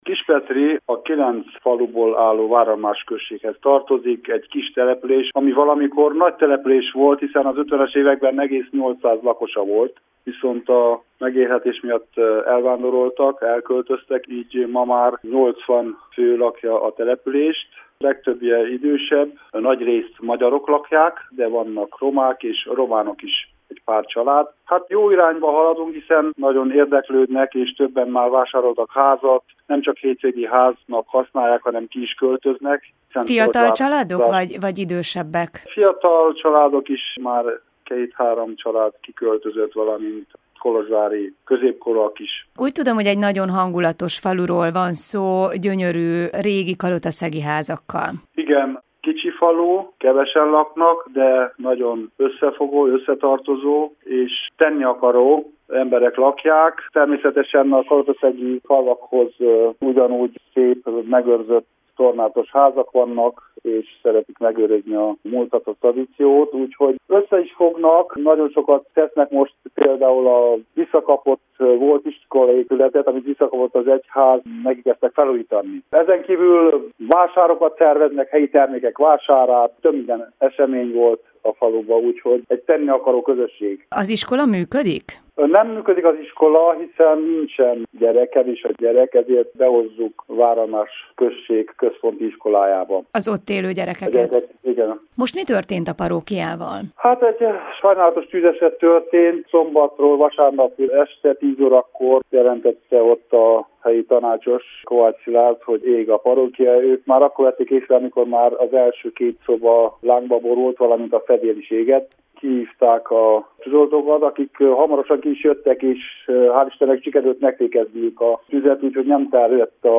Váralmás község polgármesterét kerestük, Gál-Máté István nyilatkozott rádiónknak.